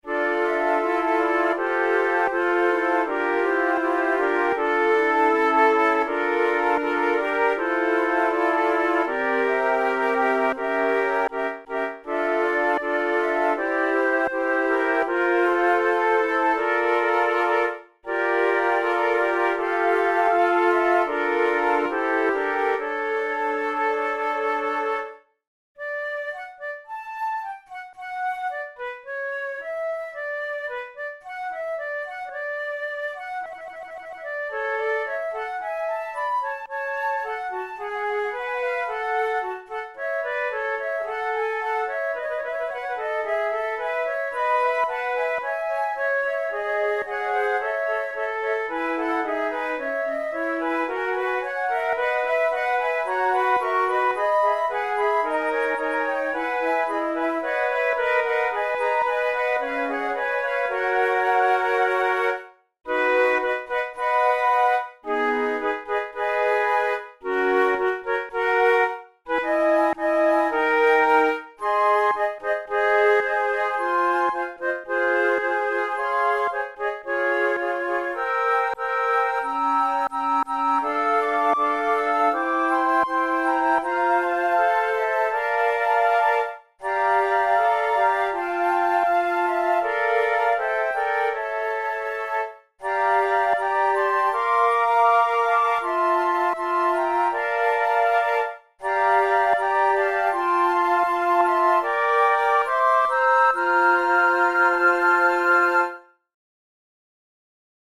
for flute quartet